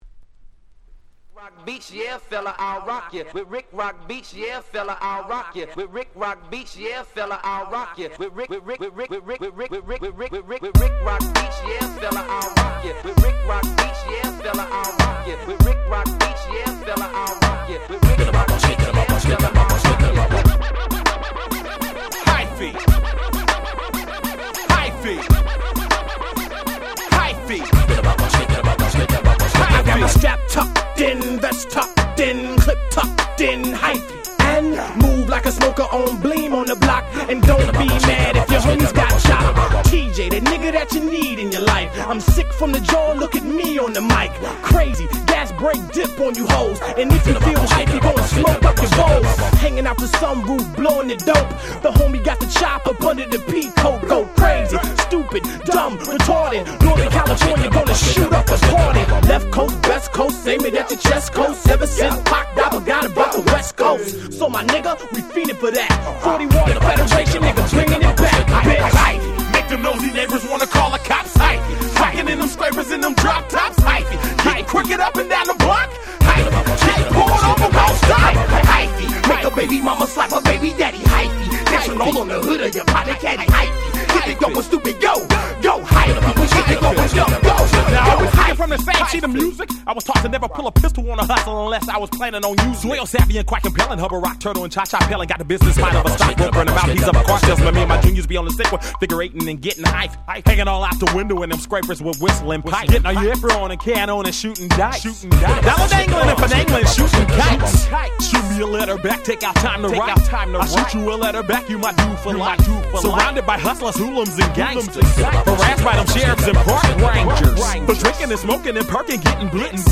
【Media】Vinyl 12'' Single
03' Smash Hit Hip Hop !!